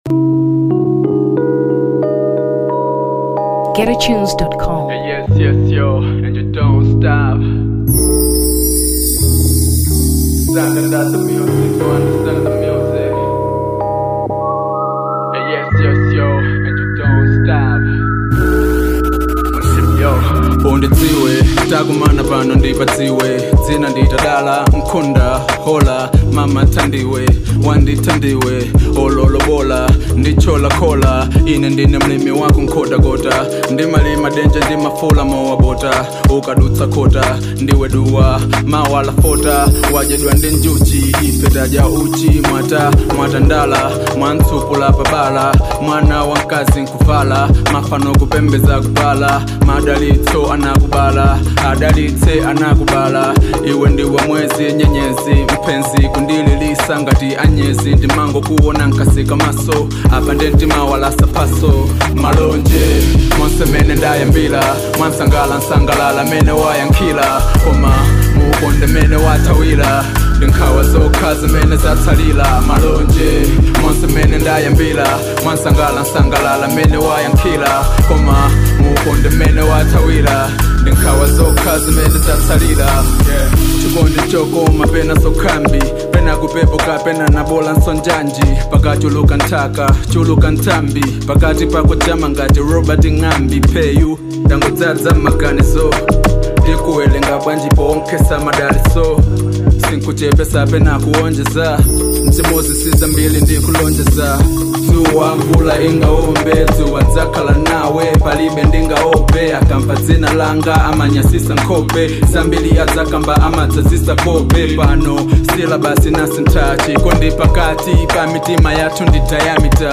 Hip Hop 2023 Malawi